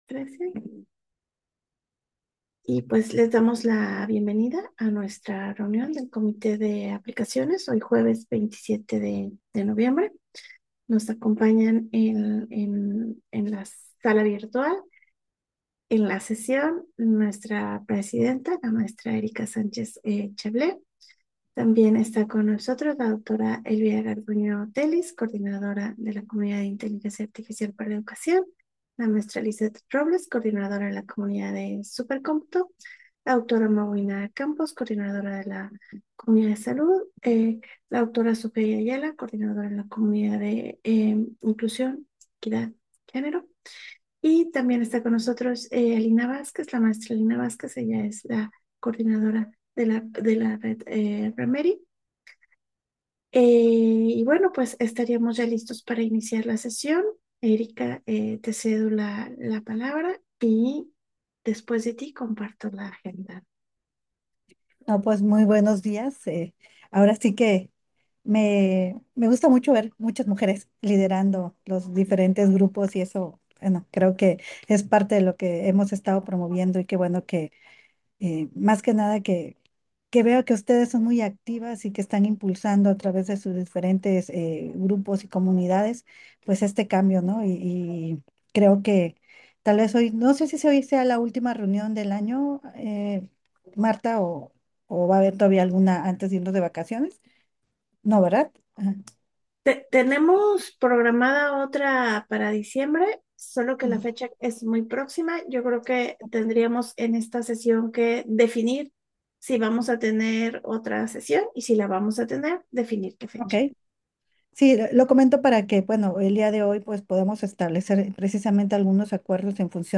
Repositorio CUDI: Reunión del comité de aplicaciones del 27 de noviembre